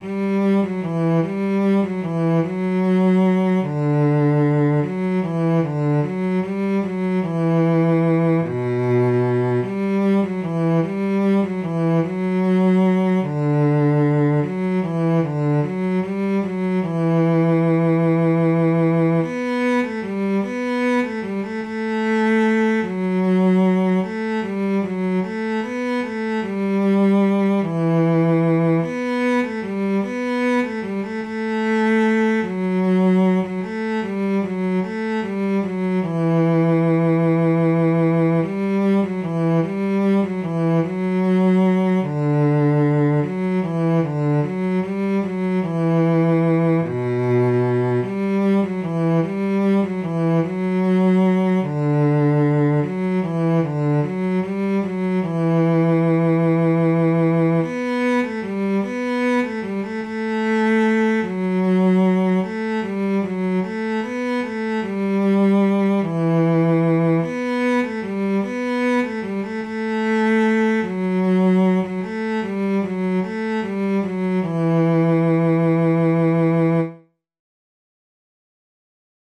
Jewish Folk Song
E minor ♩= 50 bpm